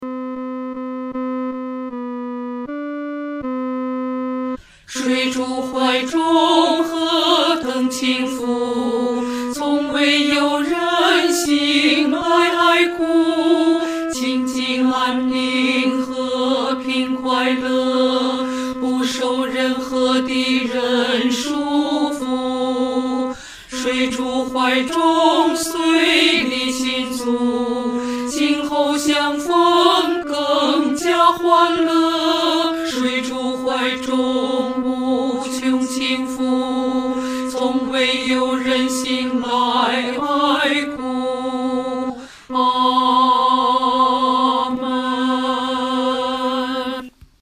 女低
其旋律、和声构成无比宁静的气氛，在丧礼中给人莫大的安慰。